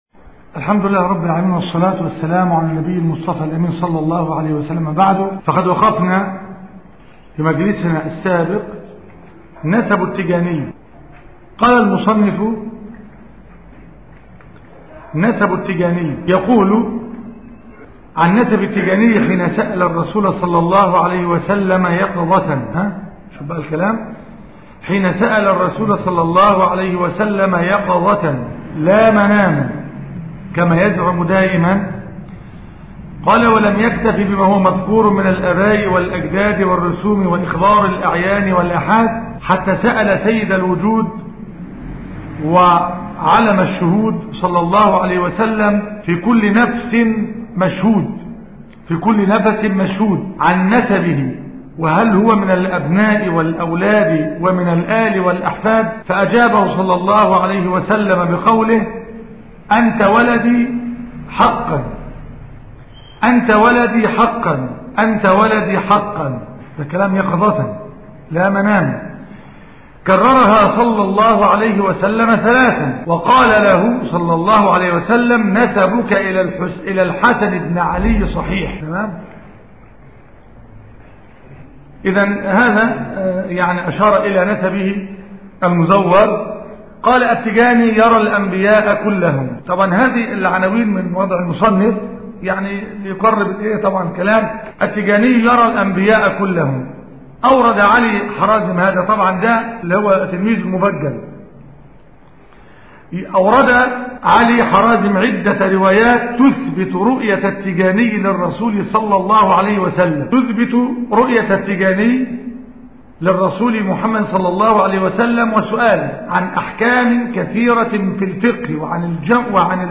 التيجانية - المحاضرة الأولى